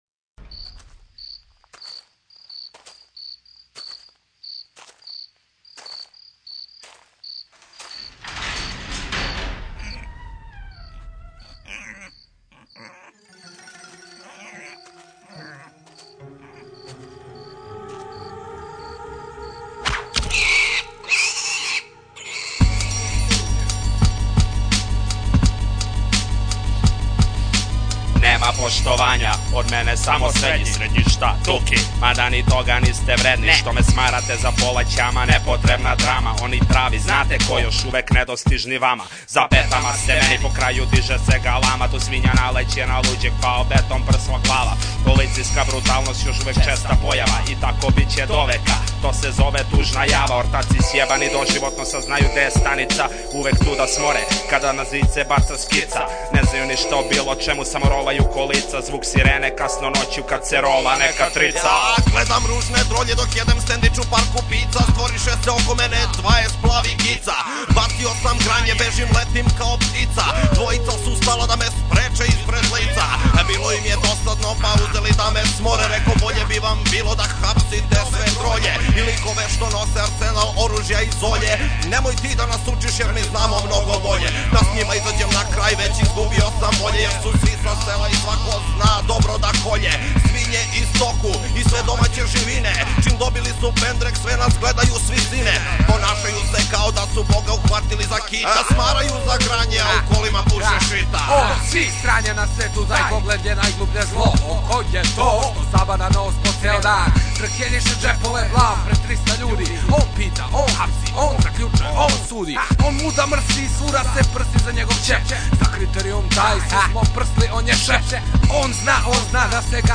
muzej (ne)objavljenih pesama domaćeg repa
ozbiljan posse cut